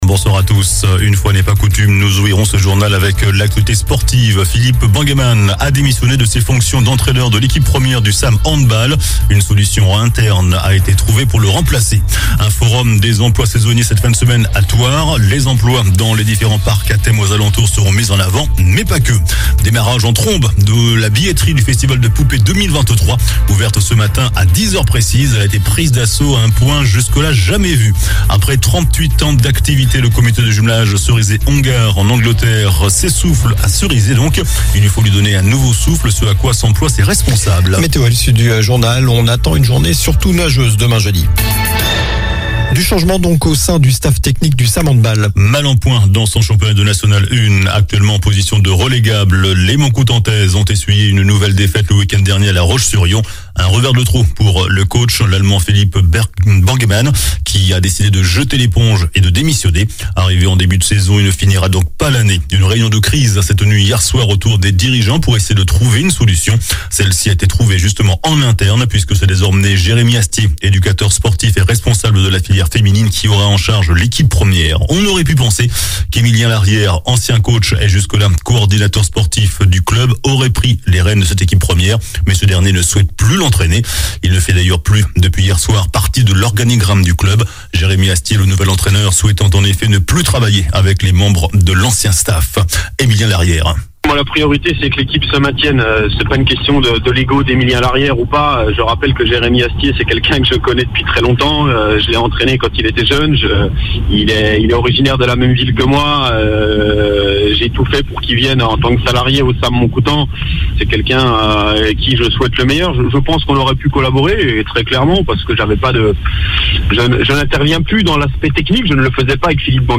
JOURNAL DU MERCREDI 15 FEVRIER ( SOIR )